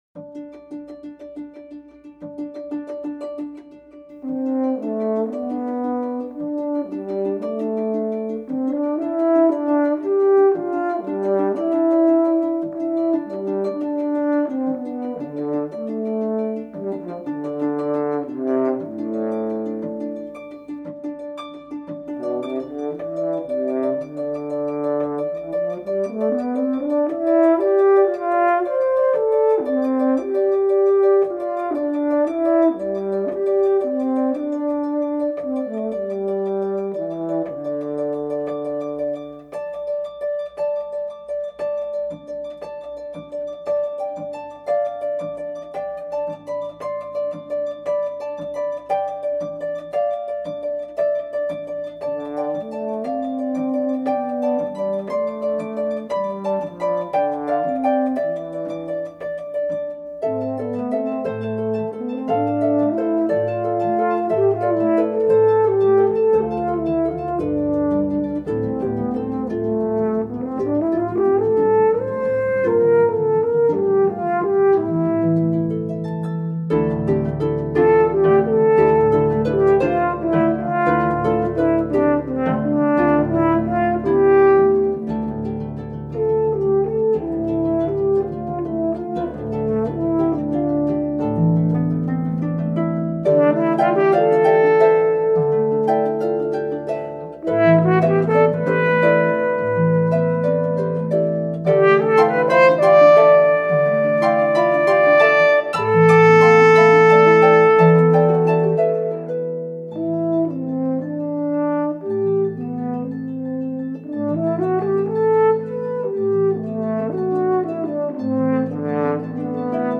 for pedal harp and horn
french horn